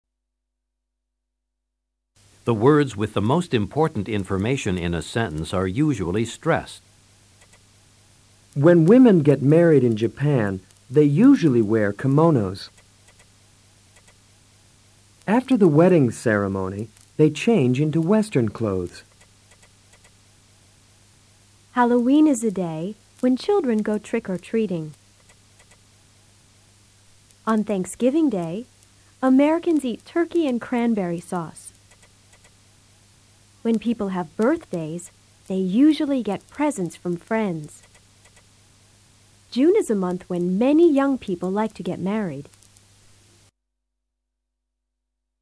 Stress and rhythm
Escucha a los profesores y presta atención al RITMO DE LECTURA de estas oraciones.